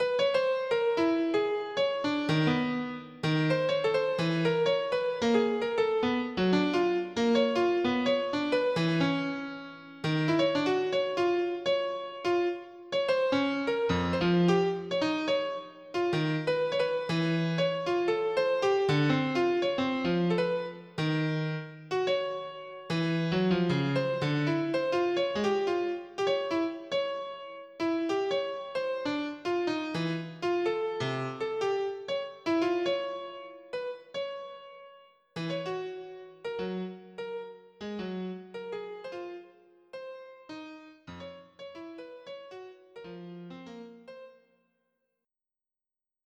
• Качество: 320, Stereo
без слов
клавишные
пианино
эксперимент